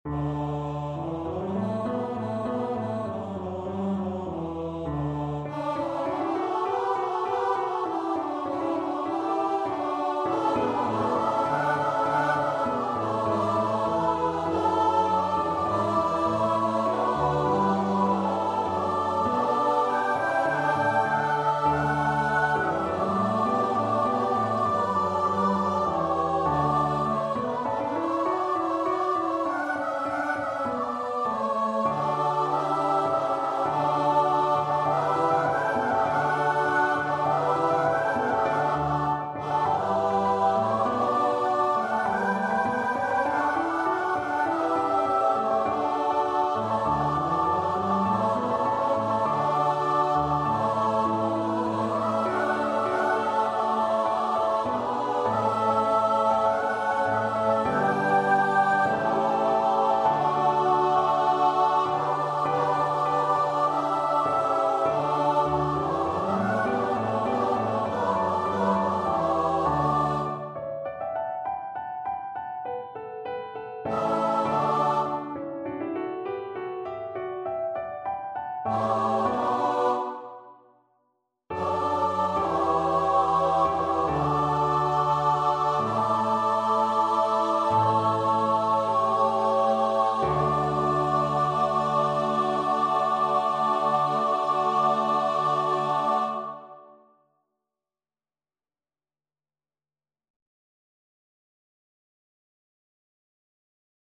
Hallelujah, Amen (Judas Maccabaeus) Choir version
Choir  (View more Intermediate Choir Music)
Classical (View more Classical Choir Music)